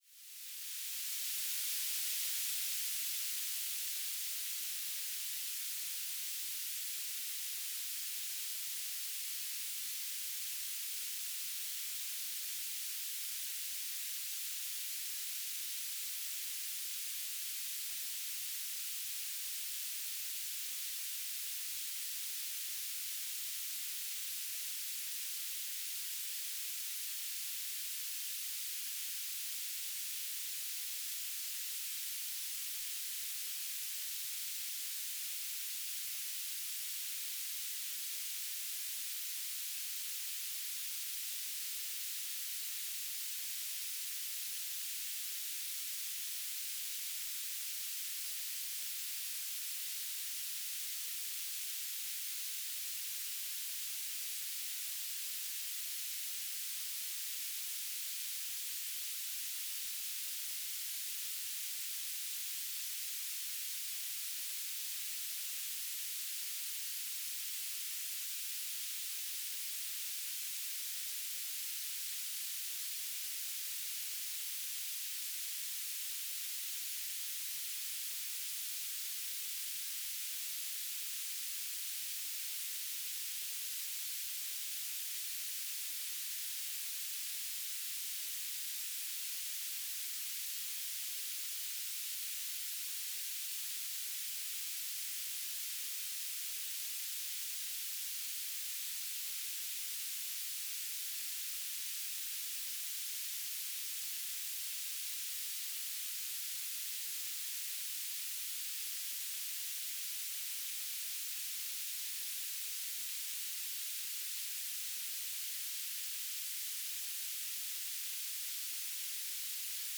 "transmitter_description": "Telemetry",